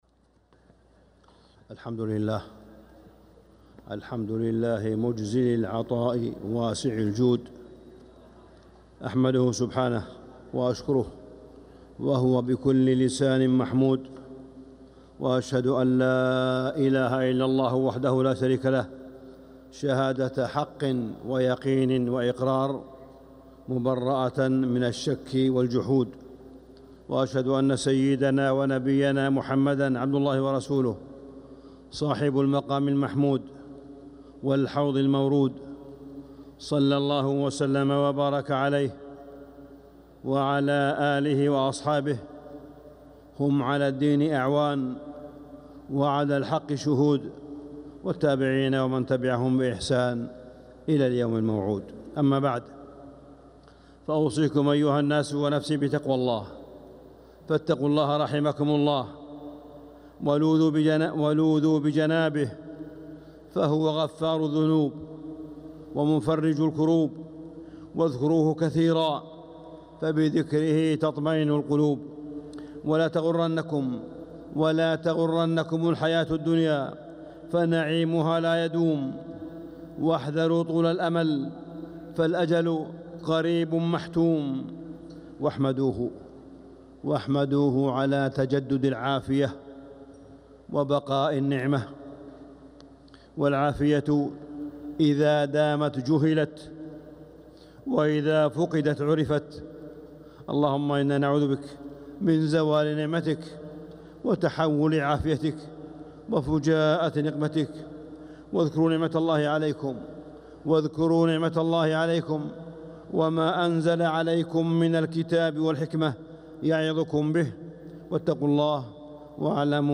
خطبة الجمعة 3-3-1446هـ | Khutbah Jumu’ah 6-9-2024 > خطب الحرم المكي عام 1446 🕋 > خطب الحرم المكي 🕋 > المزيد - تلاوات الحرمين